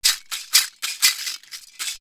PERC 16.AI.wav